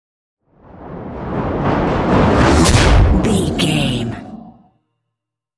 Creature whoosh to hit large
Sound Effects
Atonal
scary
ominous
haunting
eerie
woosh to hit